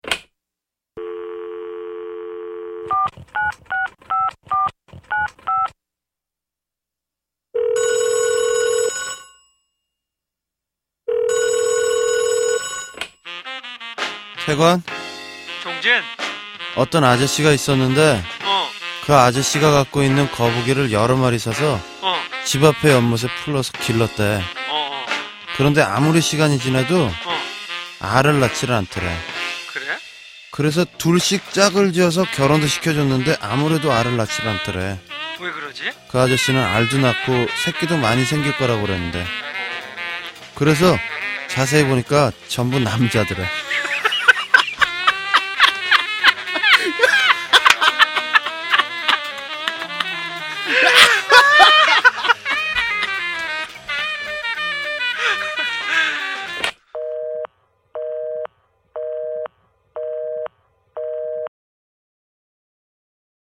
보컬, 기타
드럼